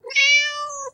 meow_D4.wav